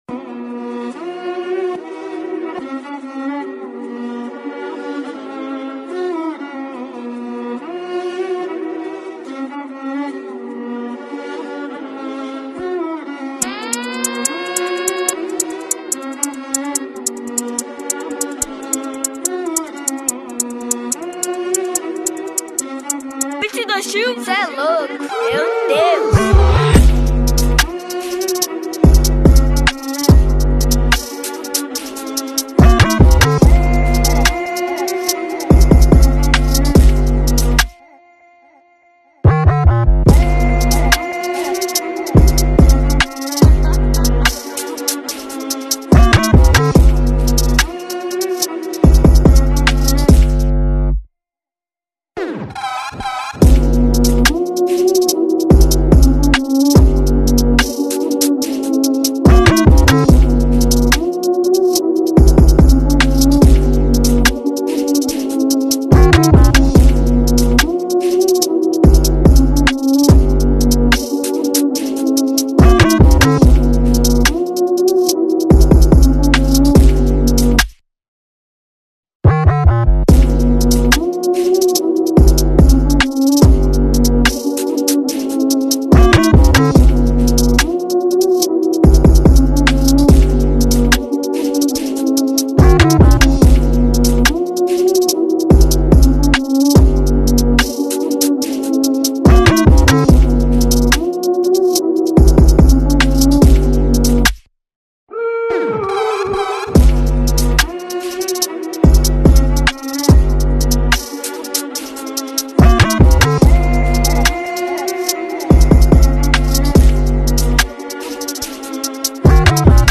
coleção de Trap Beat com uma sonoridade única